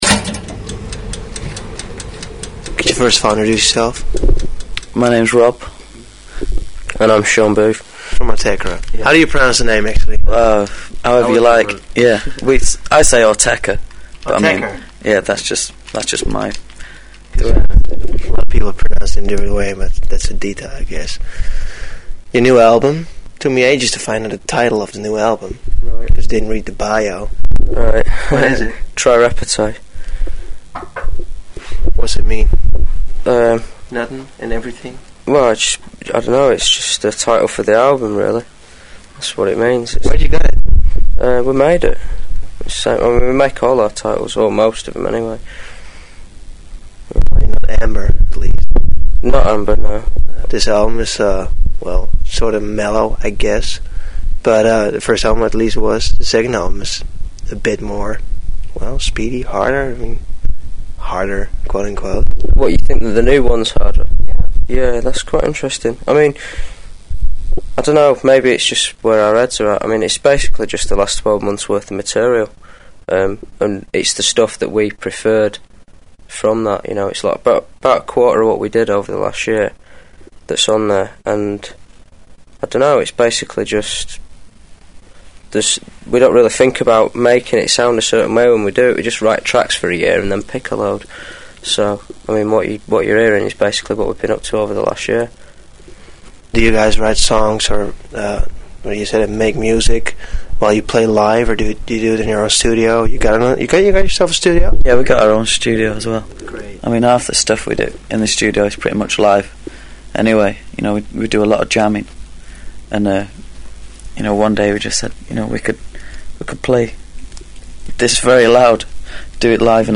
Autechre___1998_XX_XX_Toazted.com_interview.mp3